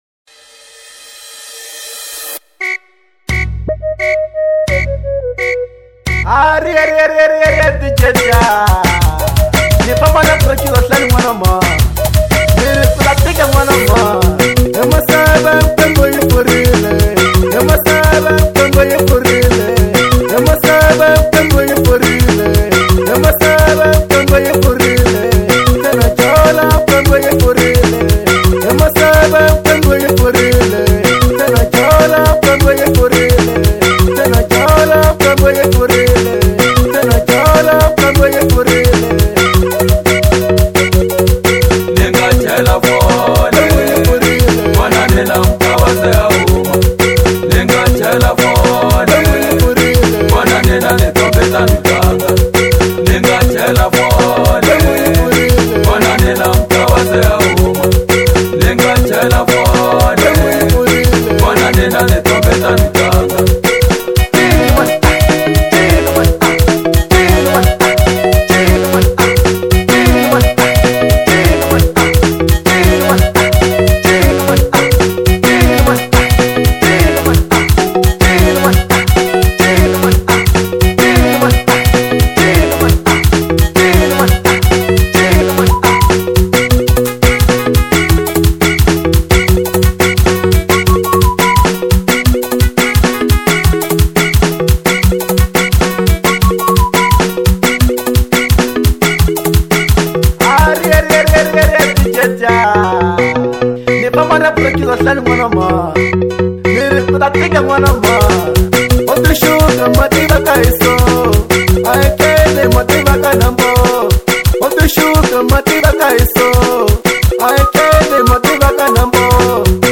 03:44 Genre : Xitsonga Size